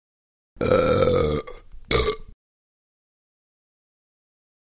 Двойная отрыжка